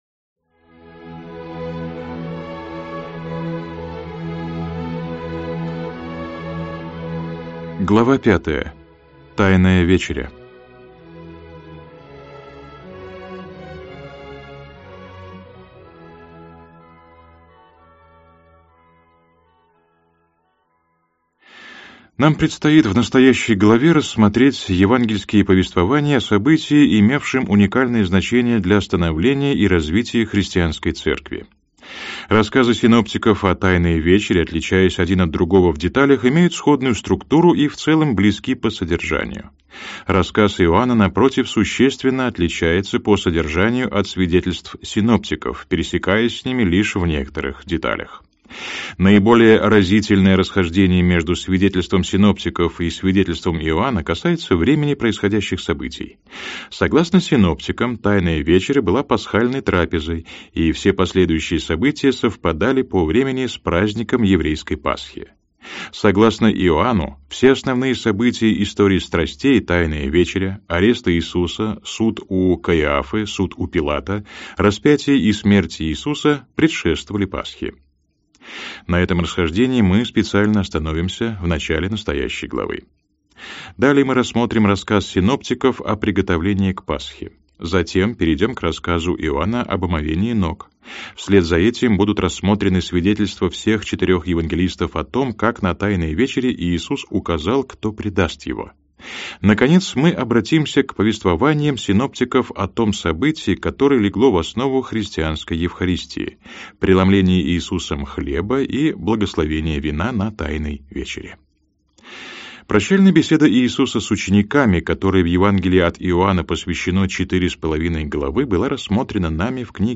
Аудиокнига Иисус Христос. Жизнь и учение.